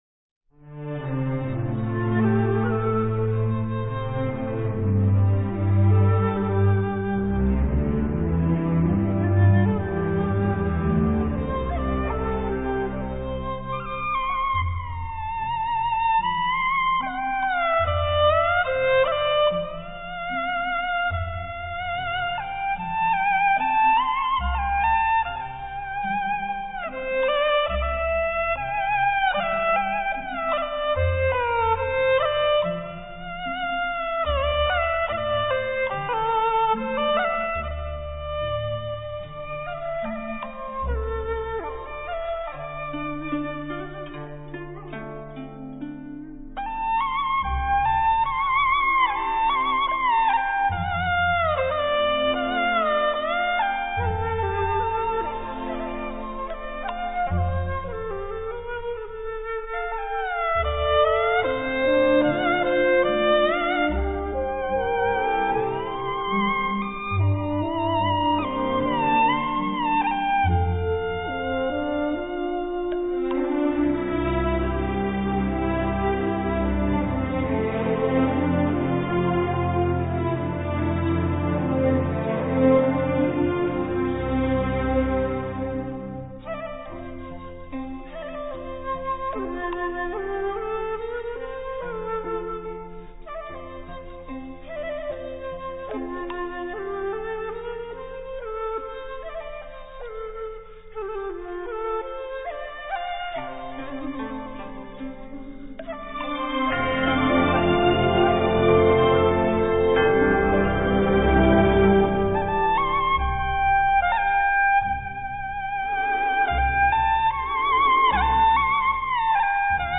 高胡 洞箫
這裡收錄的是高胡，洞箫，和弦樂演奏。高胡抑揚，洞箫幽訴，弦樂如夜之深沈。